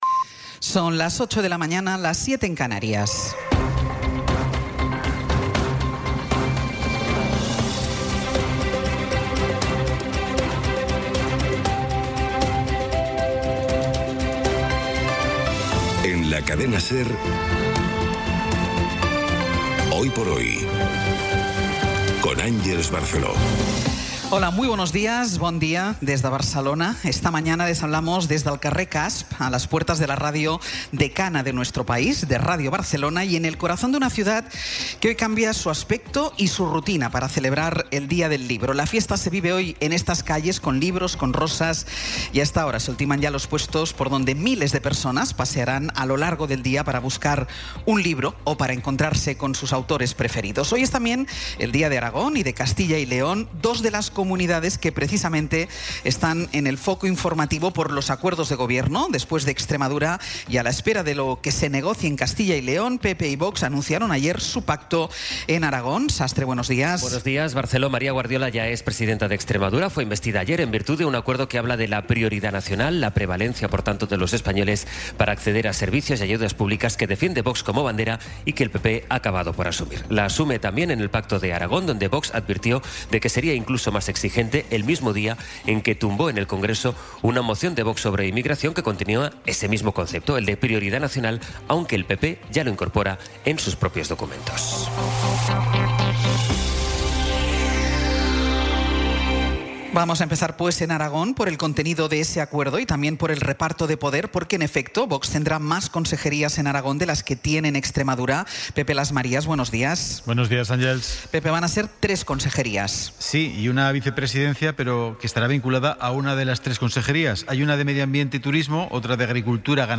Las noticias de las 08:00 20:15 SER Podcast Resumen informativo con las noticias más destacadas del 23 de abril de 2026 a las ocho de la mañana.